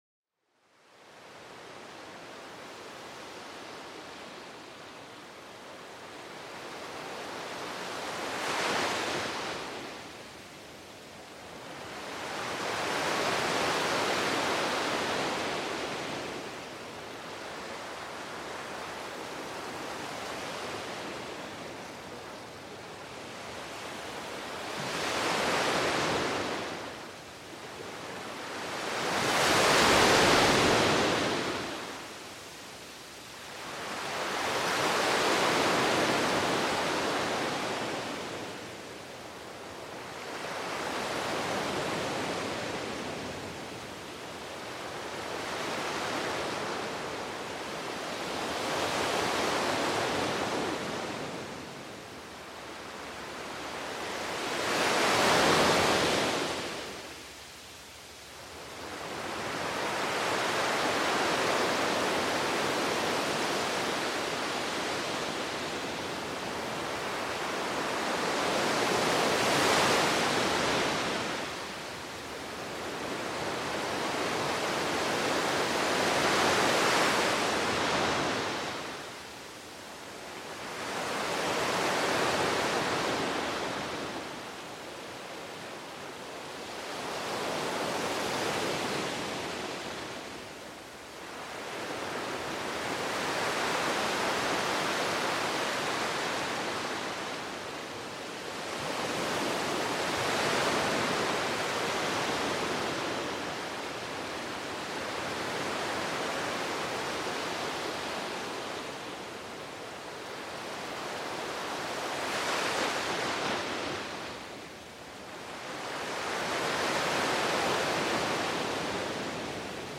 Déjate arrullar por el suave murmullo de las olas, un sonido que evoca la tranquilidad y la grandeza del océano. Esta armonía natural invita a la relajación profunda, ofreciendo una escapada hacia la serenidad.